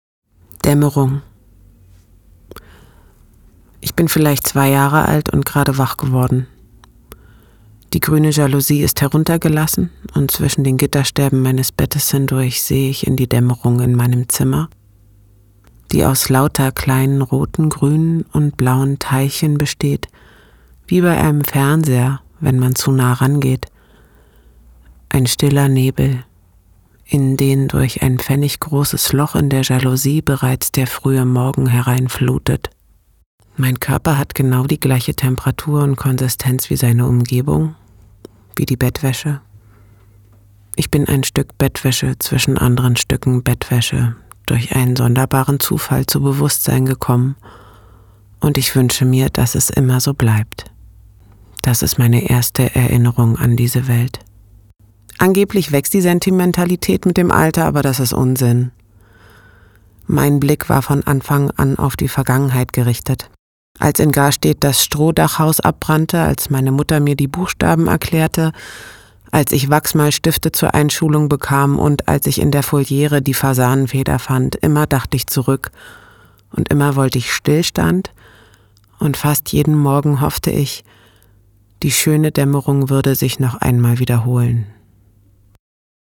dunkel, sonor, souverän, sehr variabel
Audiobook (Hörbuch)